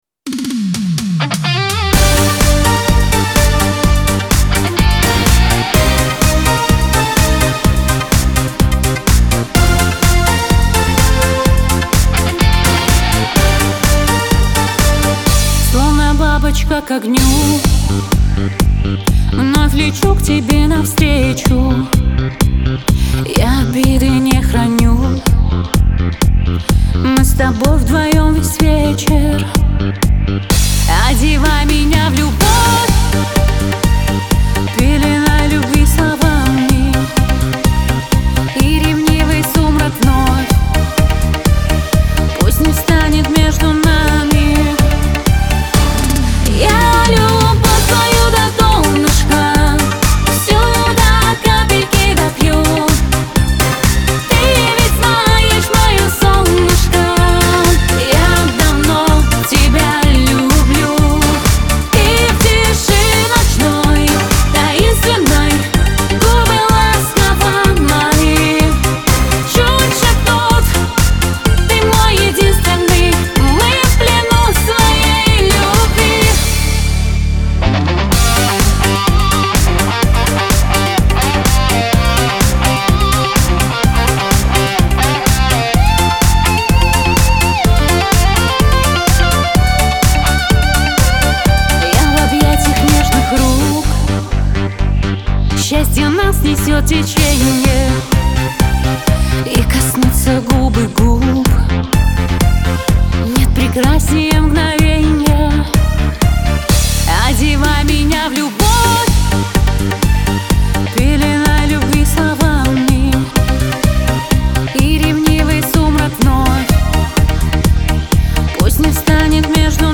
pop , диско